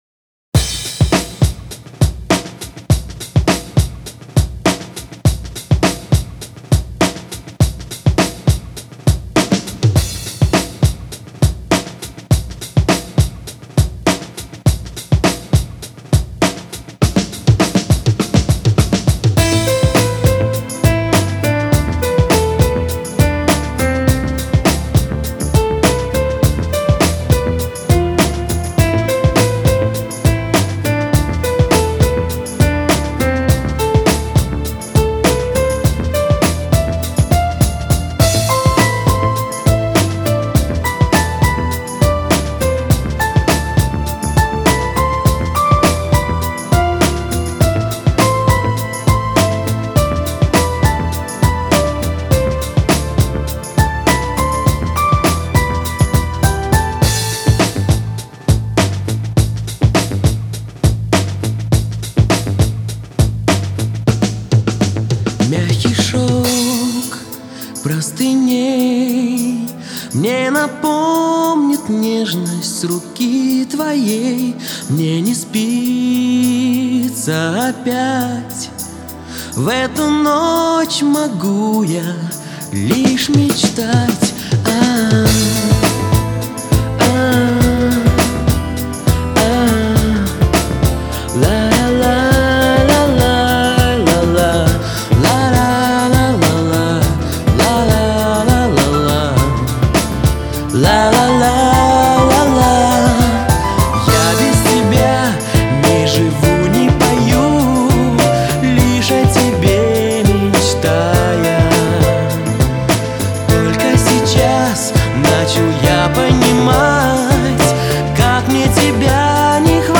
Трек размещён в разделе Русские песни / Танцевальная.